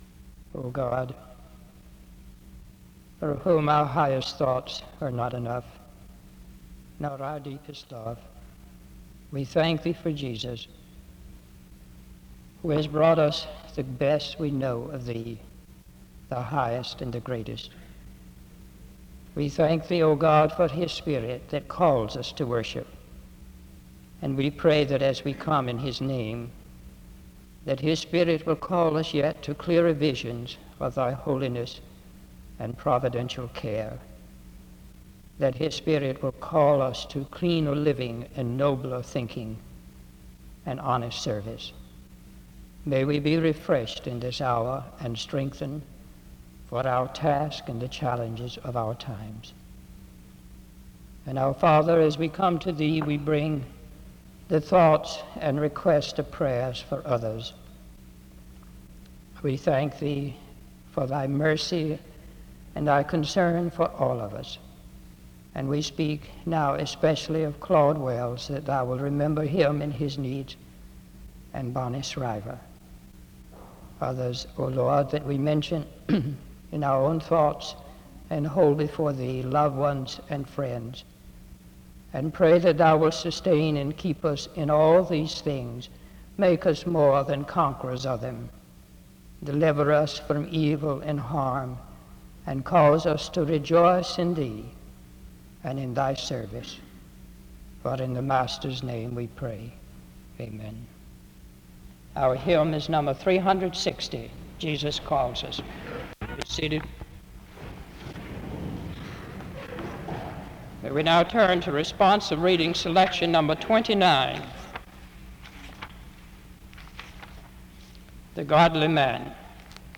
The service begins with a word of prayer from 0:00-1:40. A responsive reading takes place from 1:51-3:40. An introduction to the speaker is given from 3:40-5:23.
SEBTS Chapel and Special Event Recordings SEBTS Chapel and Special Event Recordings